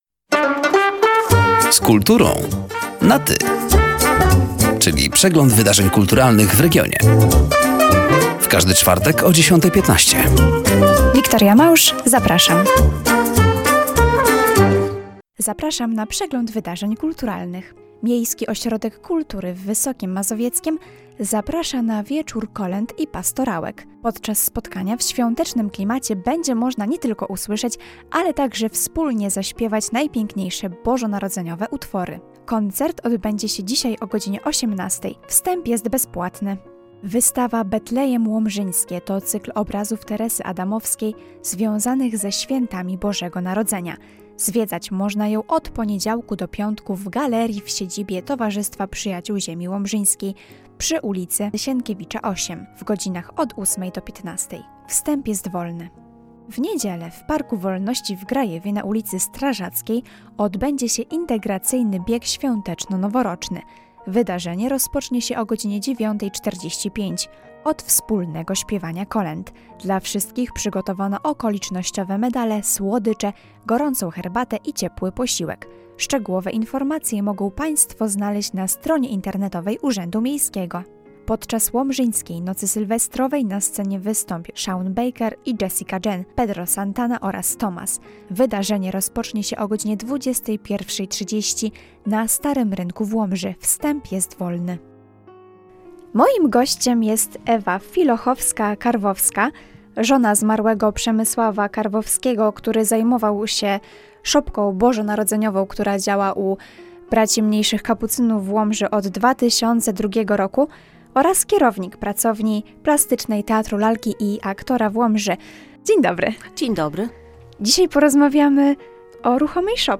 Zapraszamy do wysłuchania rozmowy oraz zapoznania się z wydarzeniami kulturalnymi: